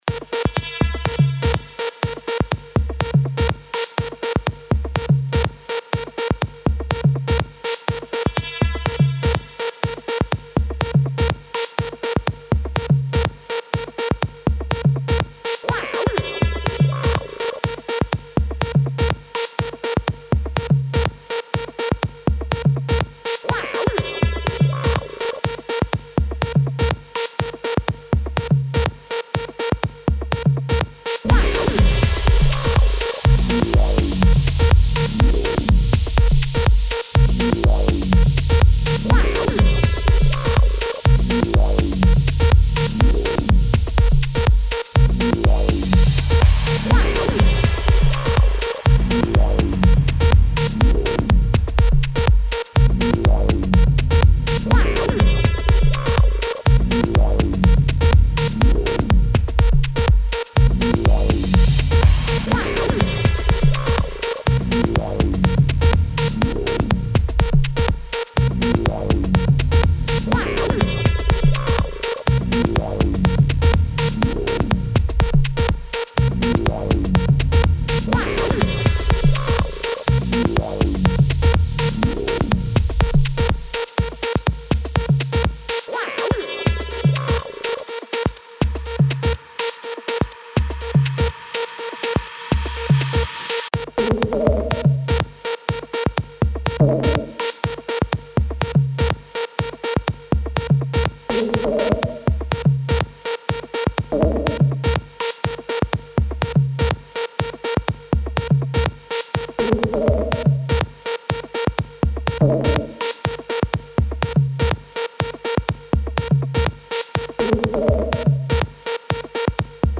Genre Downbeat